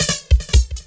DRUMFILL06-R.wav